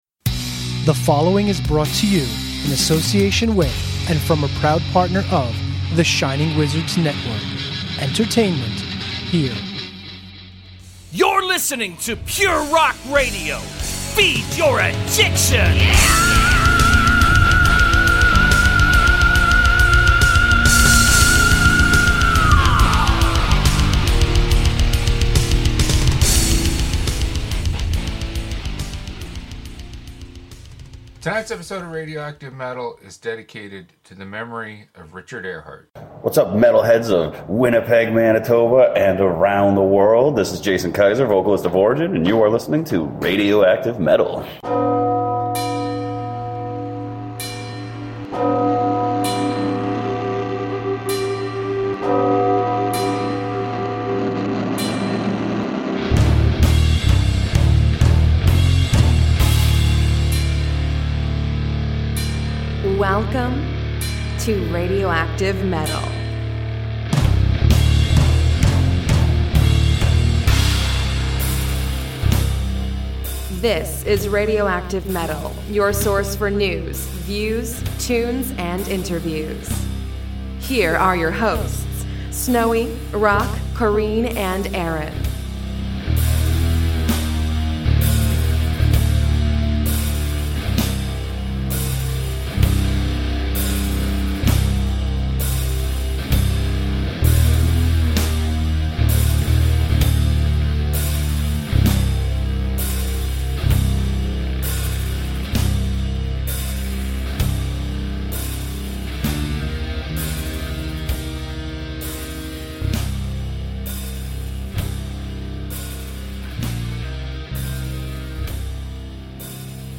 Radioactive Metal 554: Origin Story – interview with Origin